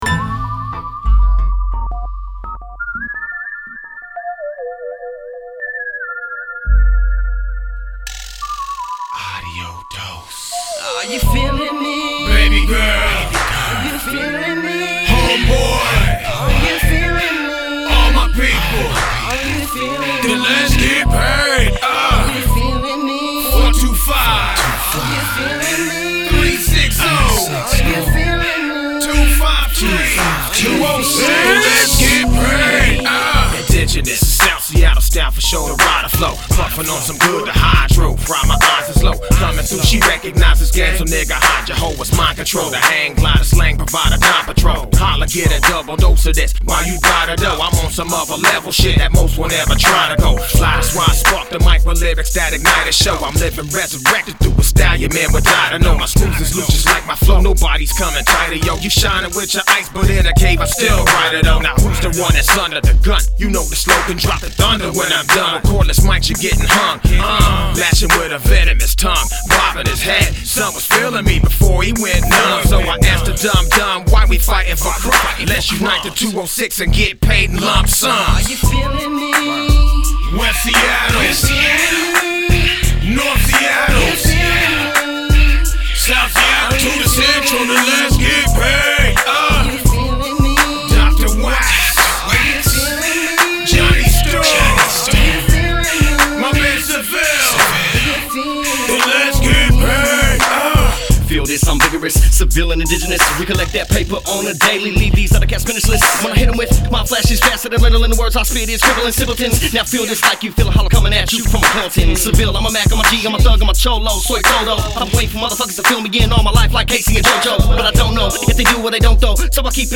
hip hop
Recorded at Ground Zero Studios